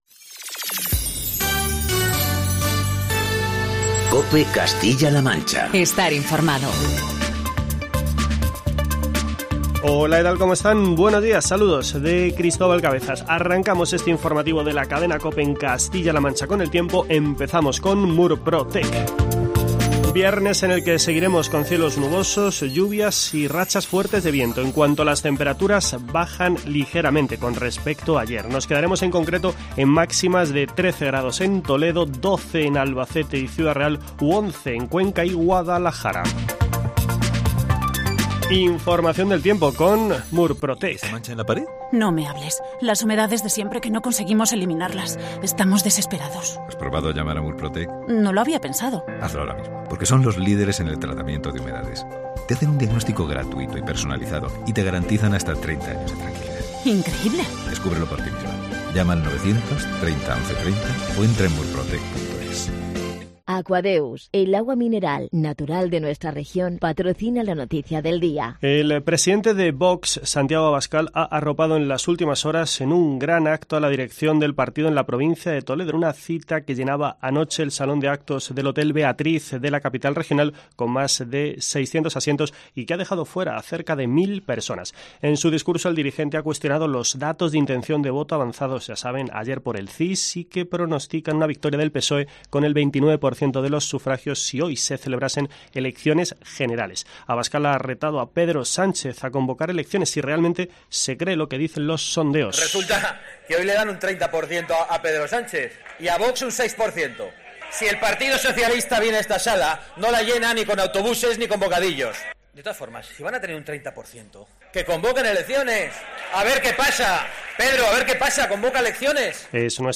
El acto de Vox en Toledo es el principal asunto que te contamos en este informativo matinal de COPE Castilla-La Mancha.